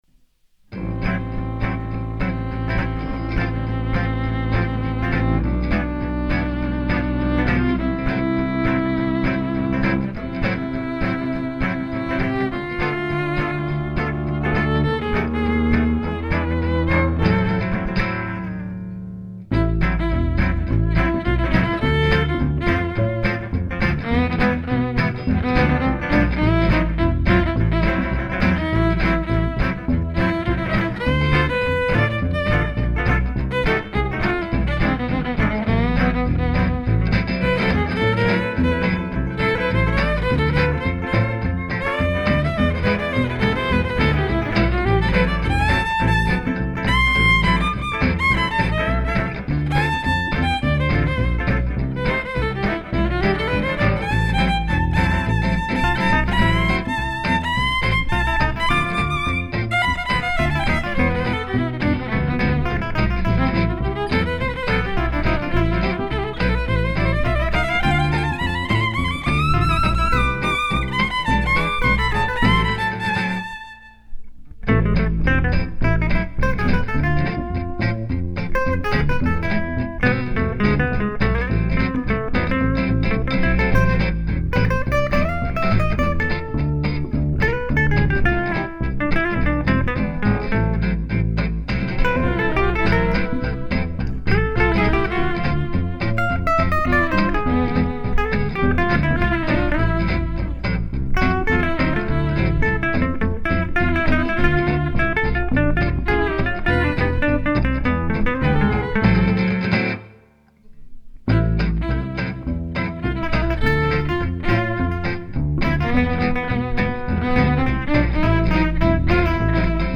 démo 2012 - enregistrée au studio Angström :